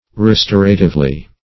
restoratively - definition of restoratively - synonyms, pronunciation, spelling from Free Dictionary Search Result for " restoratively" : The Collaborative International Dictionary of English v.0.48: Restoratively \Re*stor"a*tive*ly\, adv. In a restorative manner.
restoratively.mp3